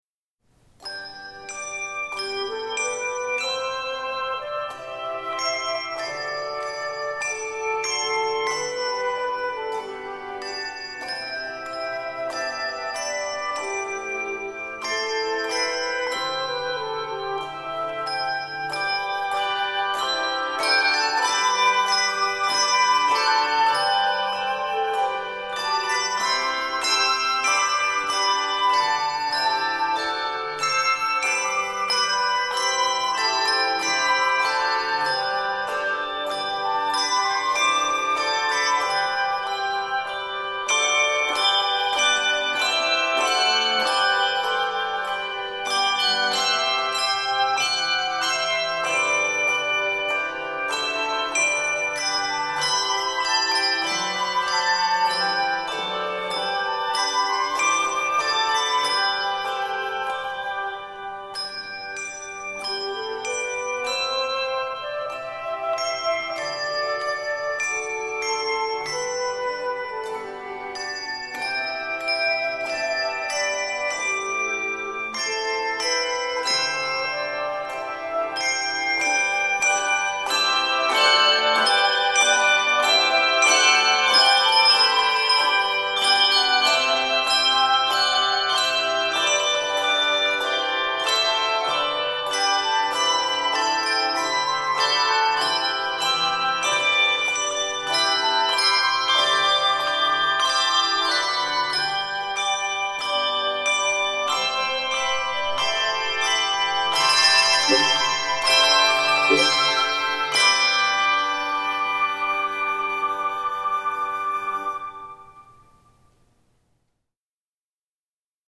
This 44-measure piece is set in G Major.